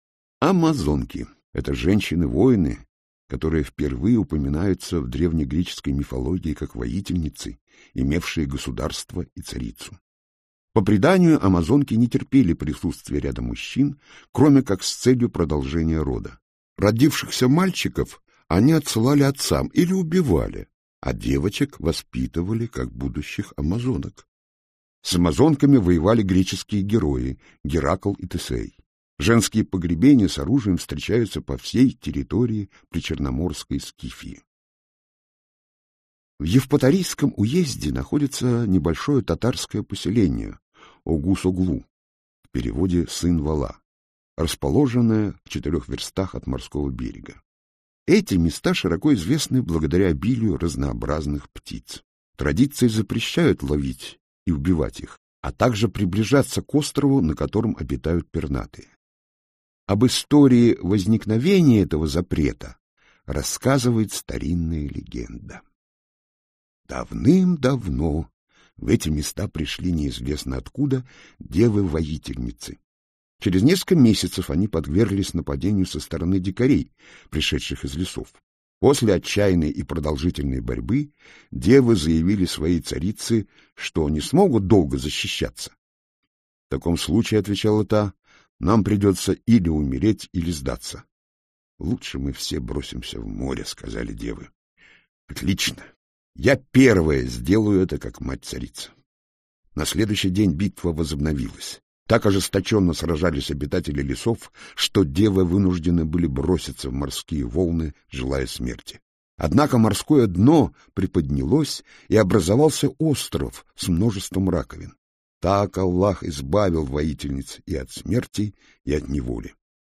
Аудиокнига Легенды и мифы Крыма | Библиотека аудиокниг